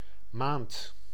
Ääntäminen
Ääntäminen France: IPA: [ɛ̃ mwɑ] Tuntematon aksentti: IPA: /mwa/ Haettu sana löytyi näillä lähdekielillä: ranska Käännös Ääninäyte Substantiivit 1. maand {f} Suku: m .